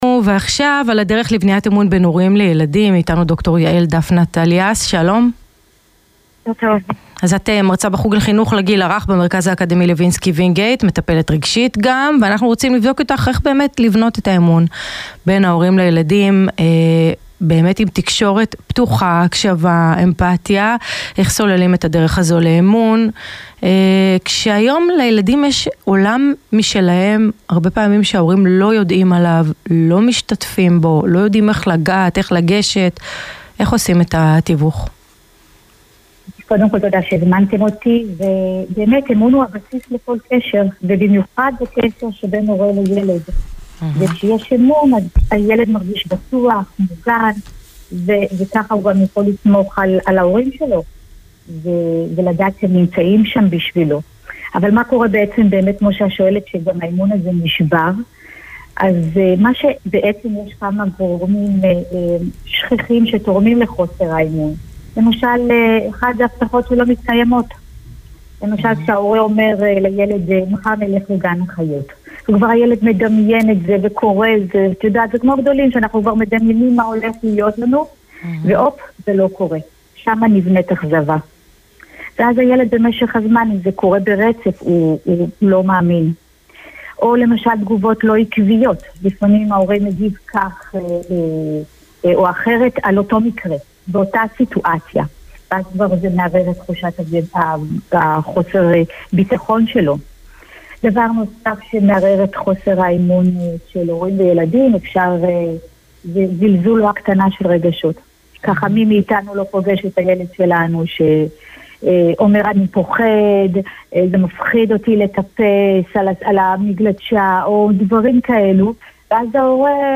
רדיו